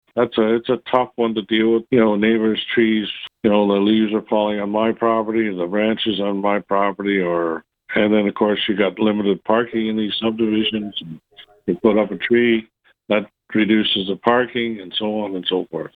Mayor Jim Harrison.
Jim-Harrison-1.mp3